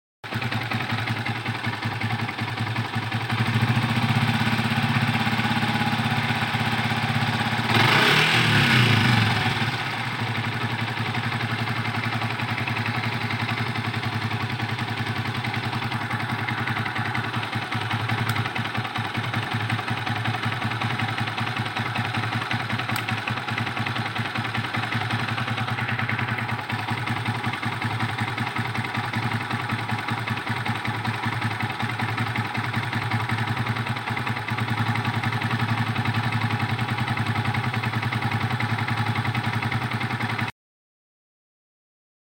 suara mesin seperti ini ada sound effects free download